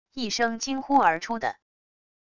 一声惊呼而出的wav音频